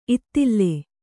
♪ ittille